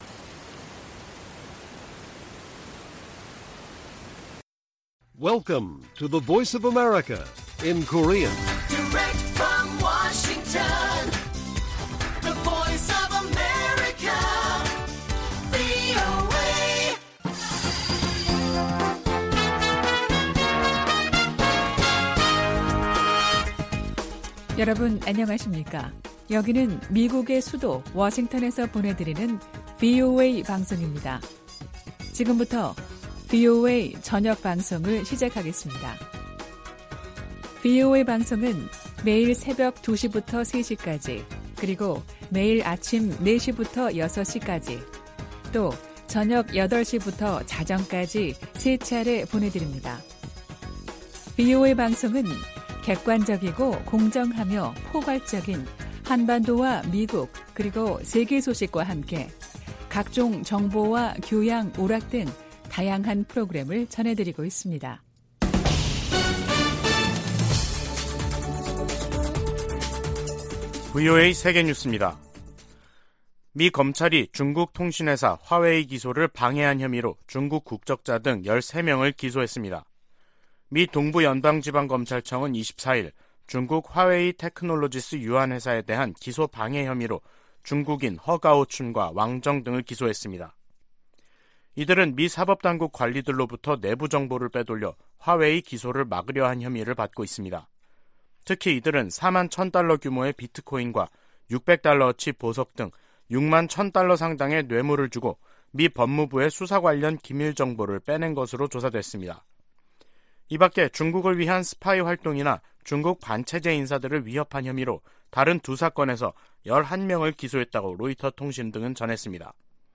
VOA 한국어 간판 뉴스 프로그램 '뉴스 투데이', 2022년 10월 25일 1부 방송입니다. 백악관은 북한이 도발을 계속하고 있는데 우려를 나타내면서, 조건없이 대화할 의향도 재확인했습니다. 국무부는 북한이 7차 핵실험을 준비 중이라는 기존의 평가를 확인하며 동맹·파트너와 만일의 사태에 대비하고 있다고 밝혔습니다. 미국의 전문가들은 시진핑 3기 중국이 북한에 더 밀착하며, 핵실험에 눈 감고 대북제재에 협조 안할 것으로 내다봤습니다.